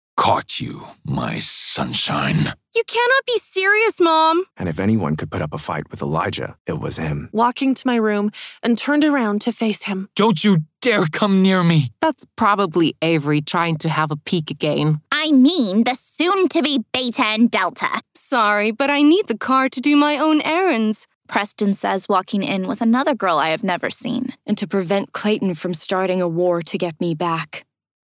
First-Draft-Audio-DEMO-REEL-1.mp3